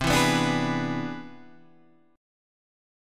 C+M9 chord